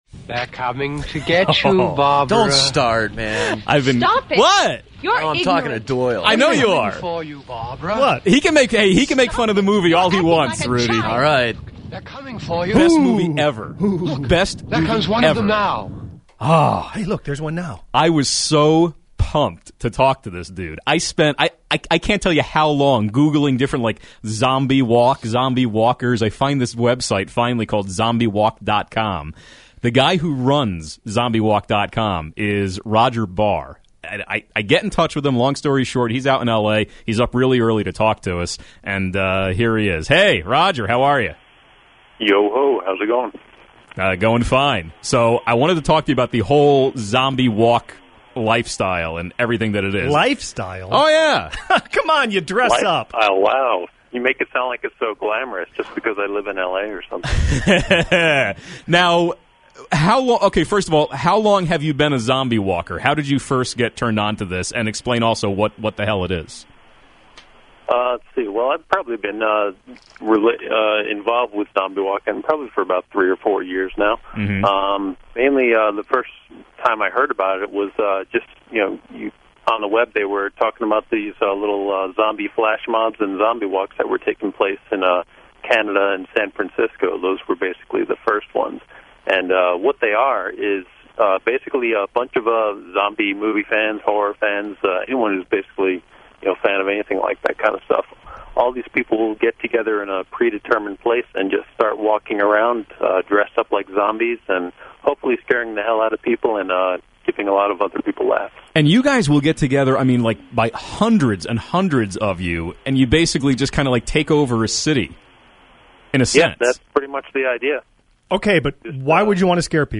Since it's a morning show and I'm located on the other side of the country, I had to get up around 5am just to talk with these guys about zombie walks. Considering the hour I was up, I'm surprised I didn't sound more like a real zombie during the interview.